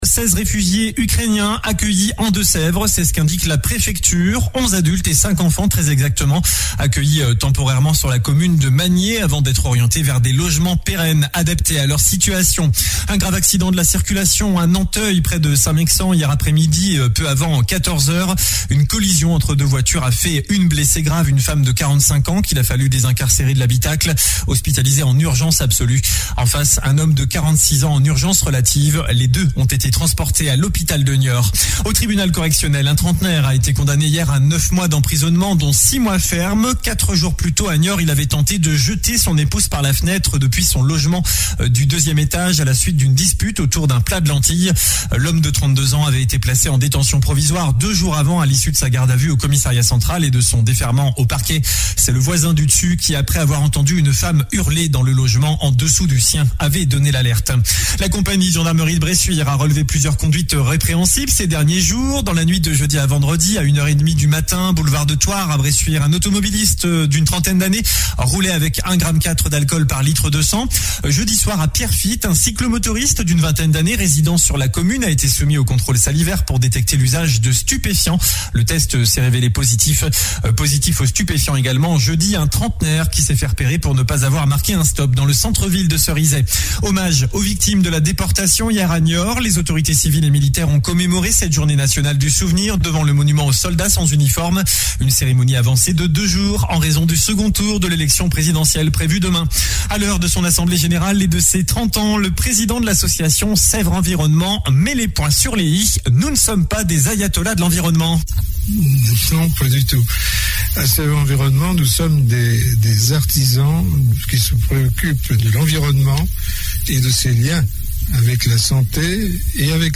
Journal du samedi 23 avril